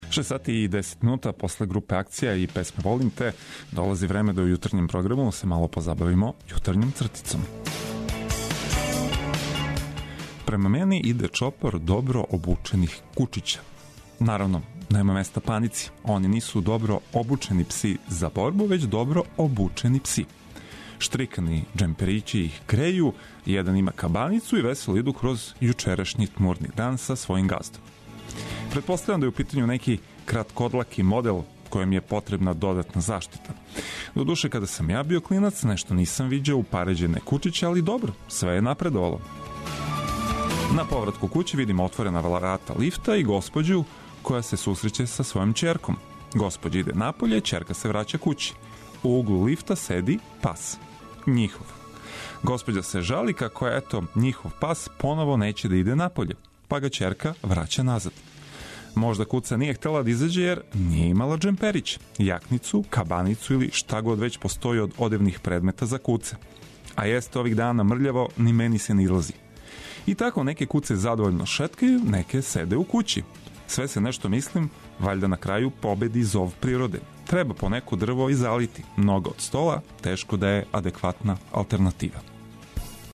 Наравно, уз вашу помоћ - слушалаца репортера, али и одличну музику са свих страна света!